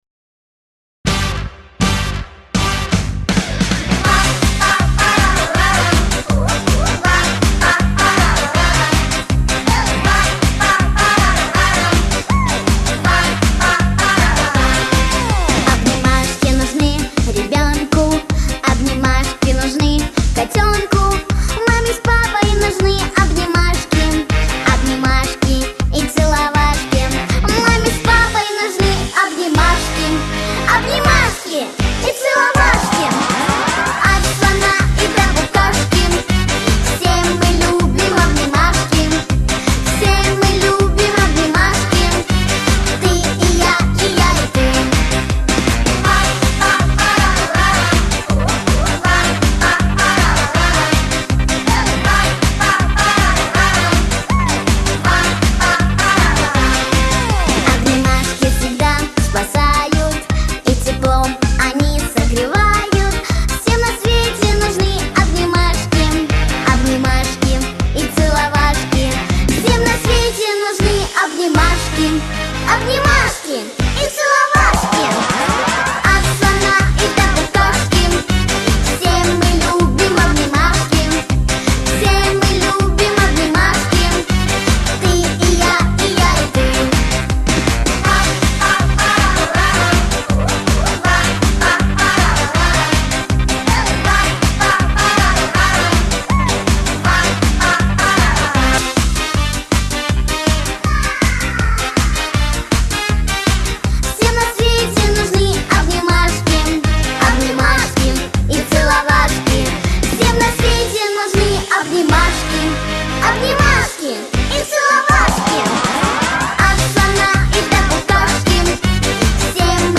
• Жанр: Детские песни
детская дискотека